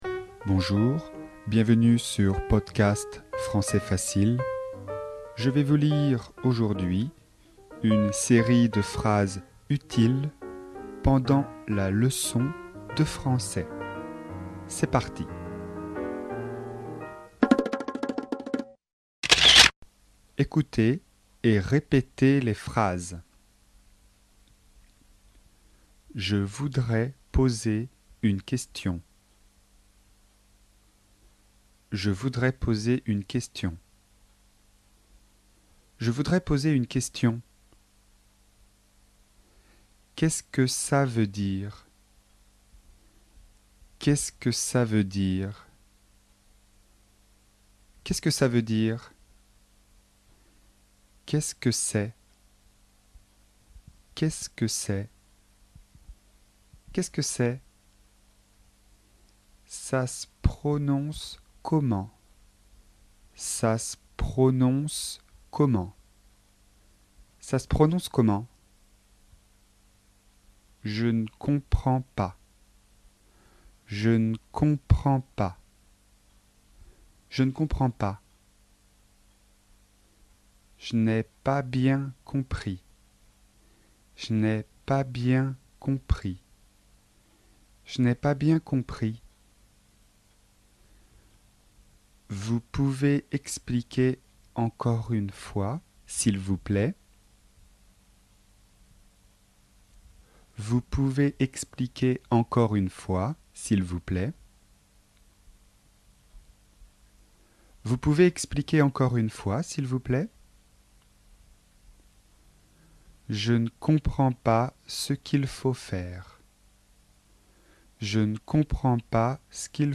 Leçon de vocabulaire, niveau débutant (A1/A2), sur le thème de l'école.